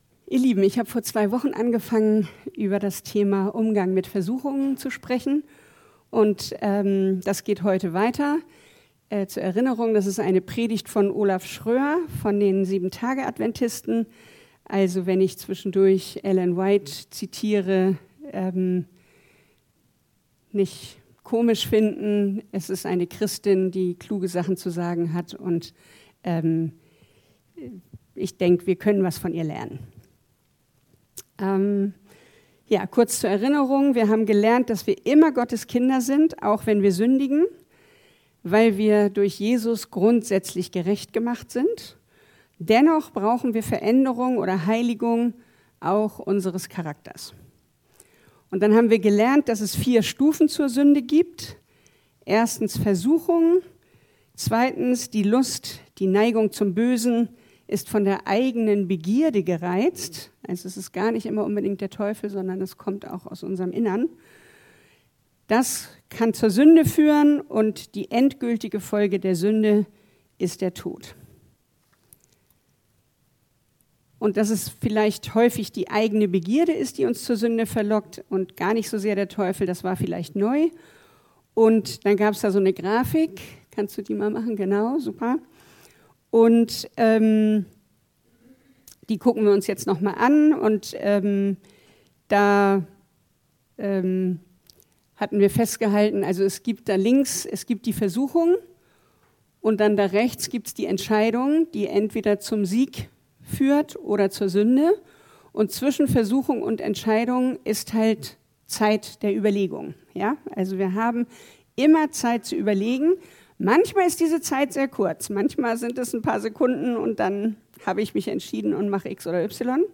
Serie: Predigt Gottesdienst: Sonntag « Du bist ein Gott